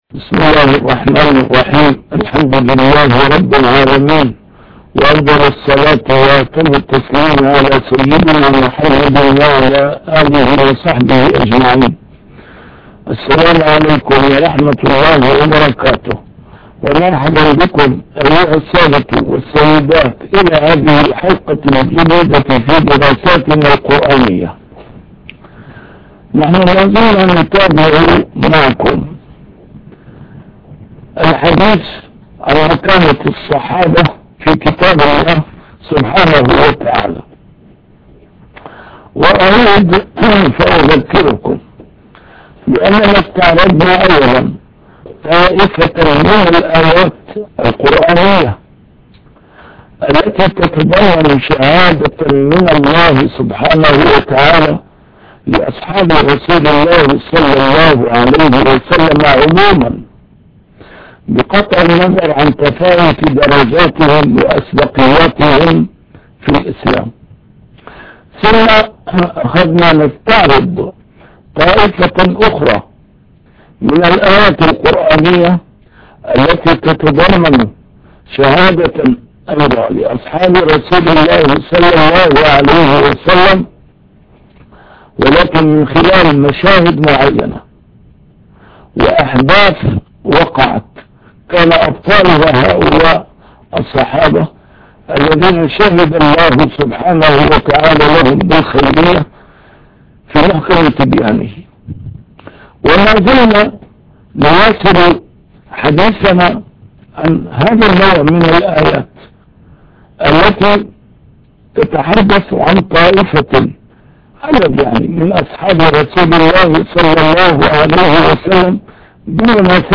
A MARTYR SCHOLAR: IMAM MUHAMMAD SAEED RAMADAN AL-BOUTI - الدروس العلمية - درسات قرآنية الجزء الثاني - 5- مكانة أصحاب رسول الله